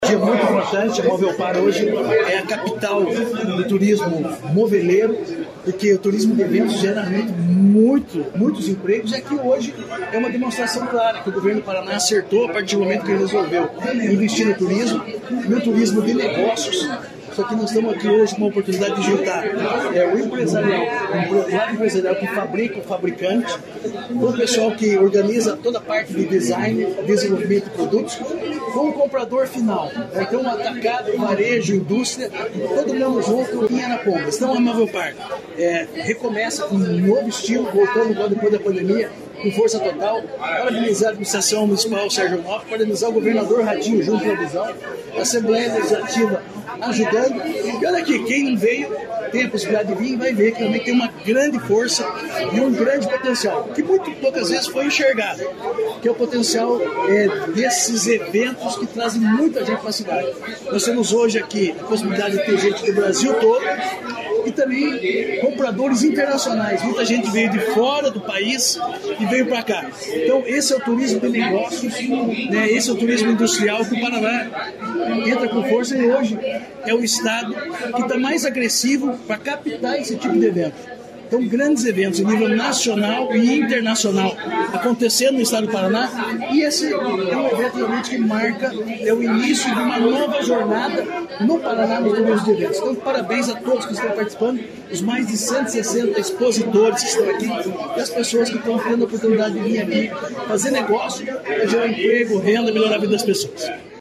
Sonora do secretário do Turismo, Marcio Nunes, sobre a Movelpar Home Show 2024